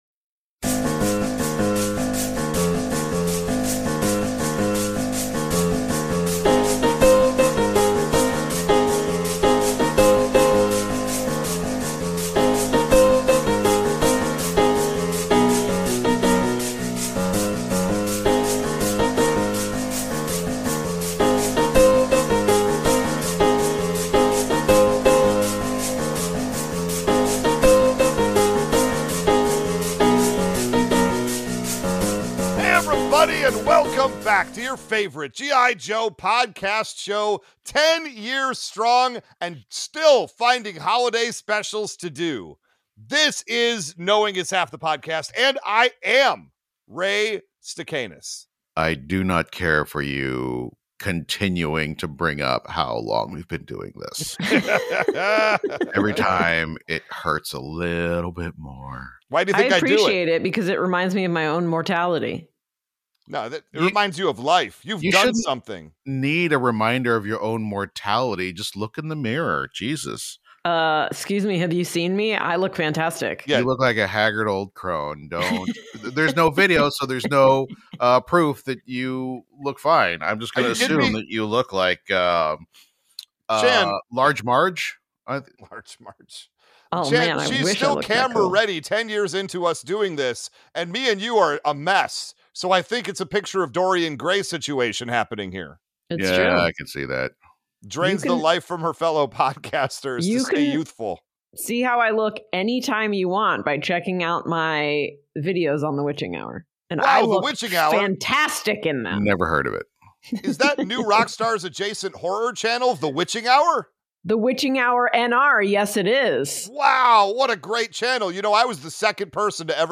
A comedy celebration of ‘80s and ‘90s cartoons. We started with the original G.I. joe and now we’re taking on everybody’s favorite animated shows from their childhood!